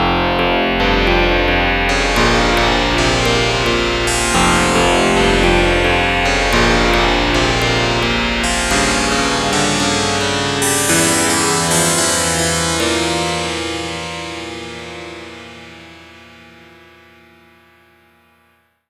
Angewendet auf eine DX7-Simulation. Man hört schon die Limitierungen des Synths und diese Kurve ist ja recht grob. An dem Sound muss man noch eine Weile rumbasteln und im Songkontext auf jeden Fall noch weiter bearbeiten Anhänge Sitar F 110bpm 1.mp3 276,1 KB